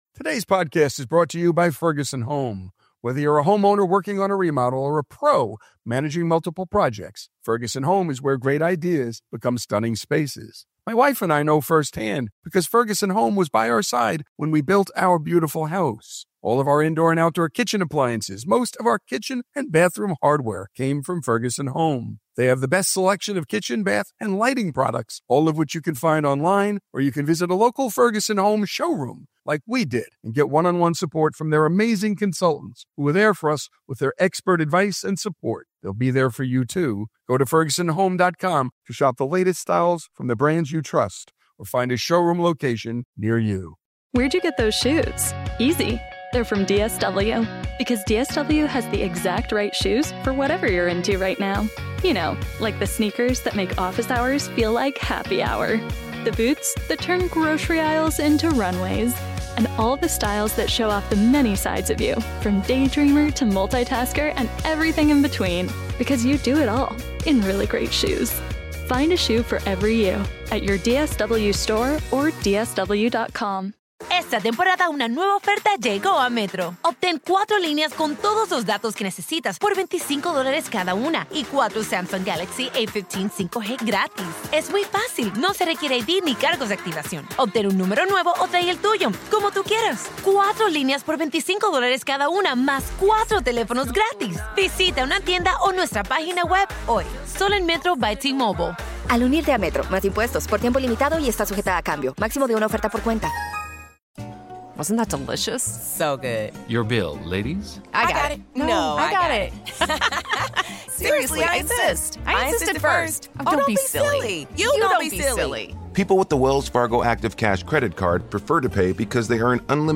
Here's Randall Wallace himself to tell the story of how the film came to be.